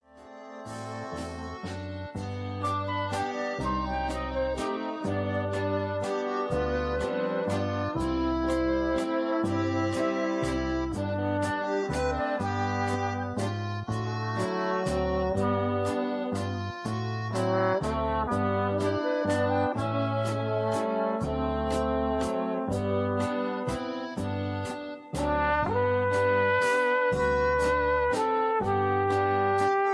Key-G
Tags: backing tracks , irish songs , karaoke , sound tracks